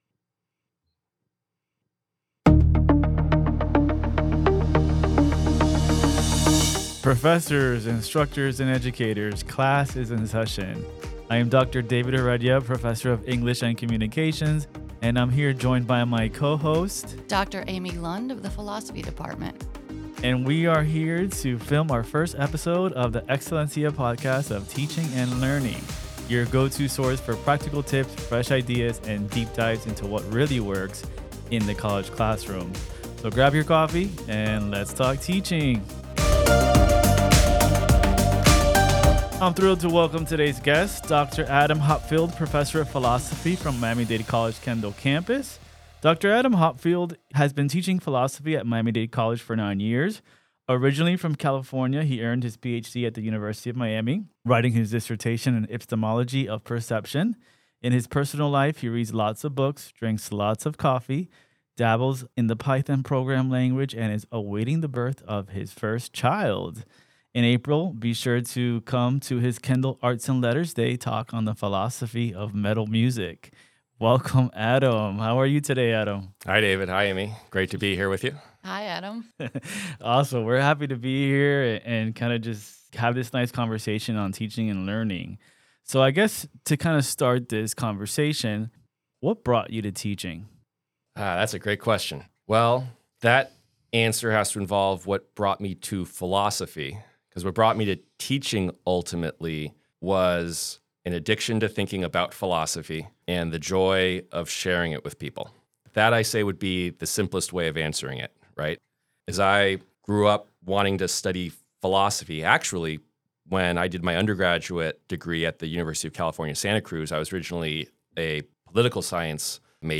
So grab a cup of coffee and enjoy this conversation between great educational minds.